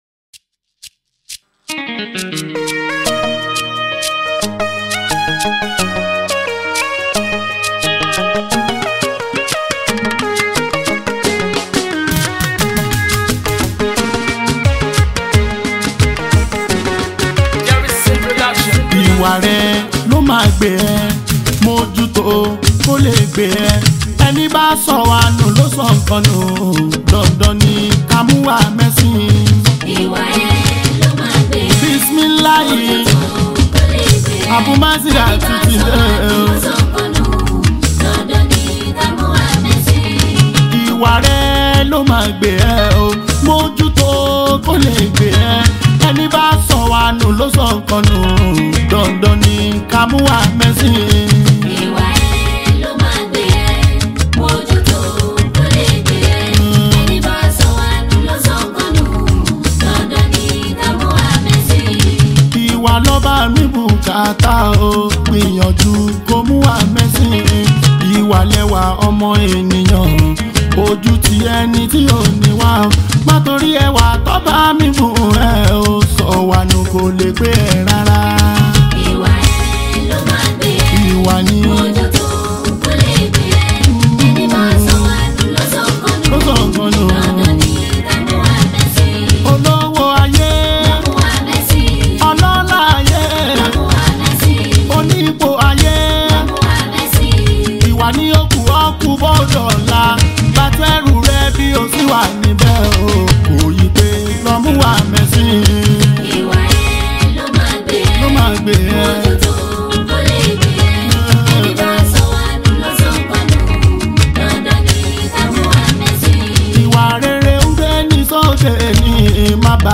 Yoruba Highlife, Yoruba Islamic Music
Nigerian Yoruba Islamic Music Track
especially if you’re a lover of Yoruba Fuji Sounds